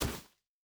Land Step Grass A.wav